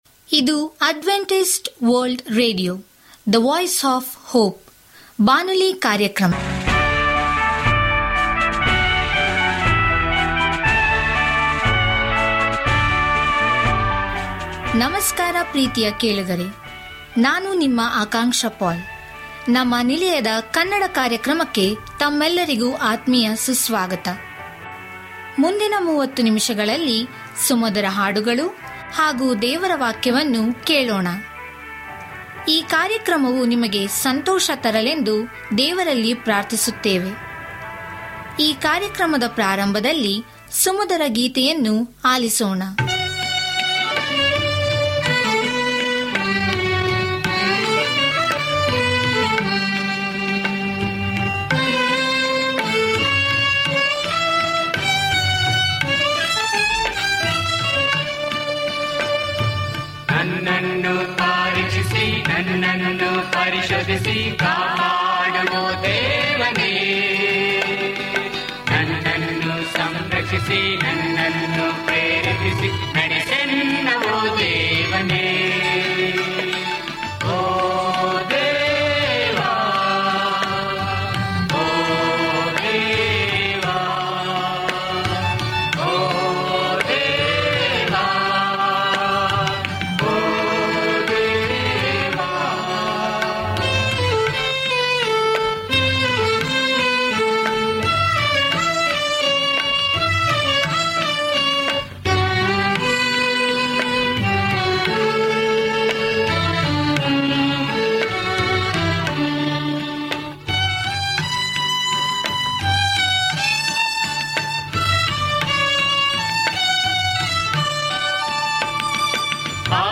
Radio programs in Kannada / ಕನ್ನಡ / Kannaḍa for Karnataka, Kerala, Maharashtra, Andhra Pradesh, Goa, India, by Adventist World Radio